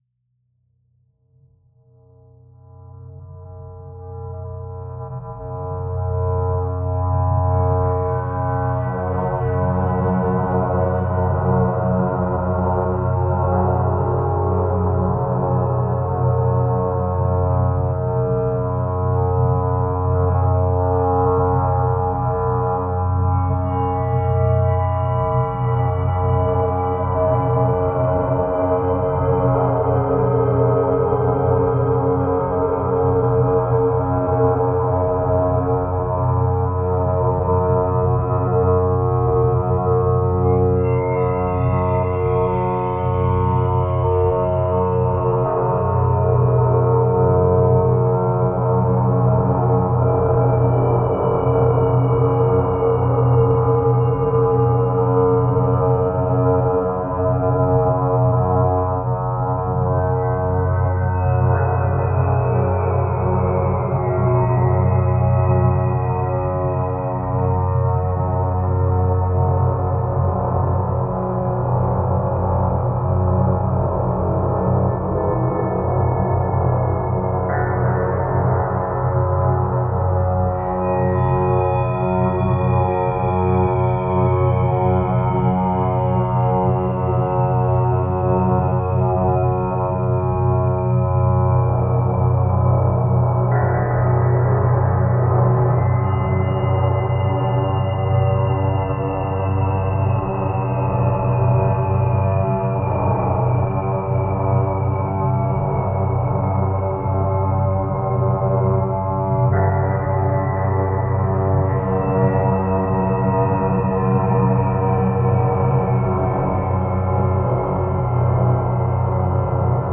Genre: Drone.